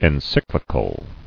[en·cyc·li·cal]